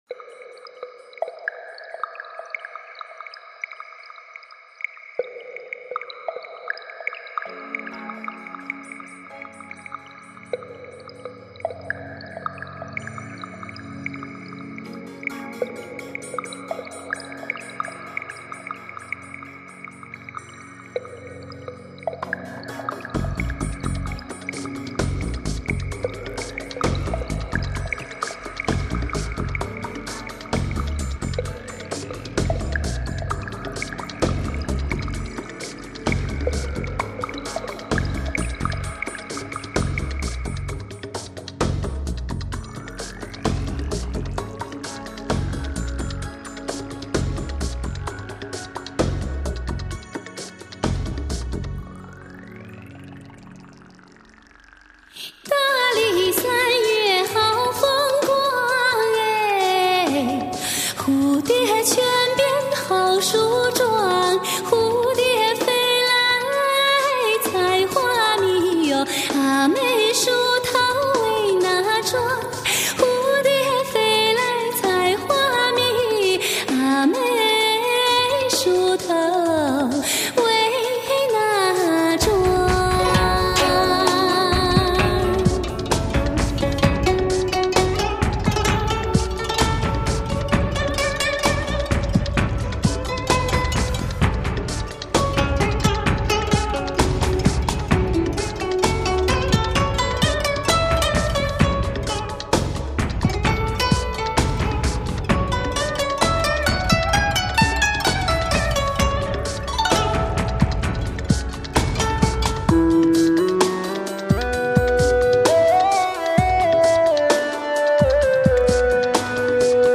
唱片类型：汽车音乐
試聽曲 蝴蝶似乎飛到山洞裡取山泉